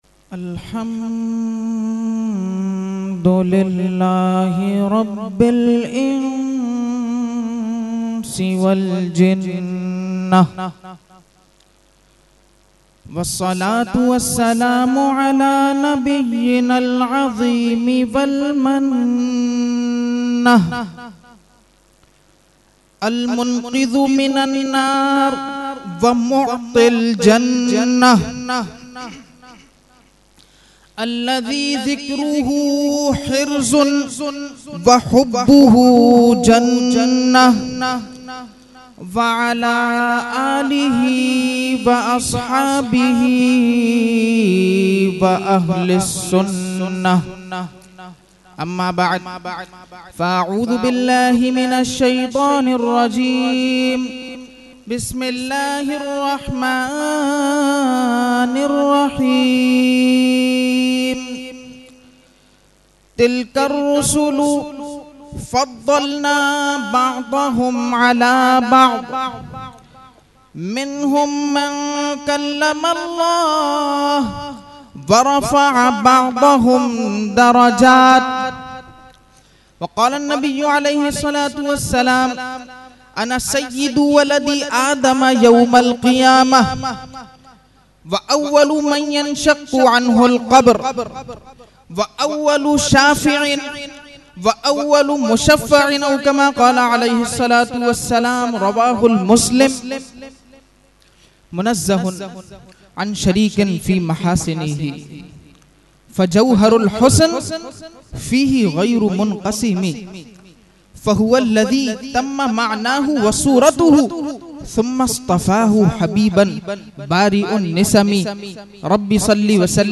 Mehfil e Jashne Subhe Baharan held on 16 September 2024 at Dargah Alia Ashrafia Ashrafabad Firdous Colony Gulbahar Karachi.
Category : Speech | Language : UrduEvent : Jashne Subah Baharan 2024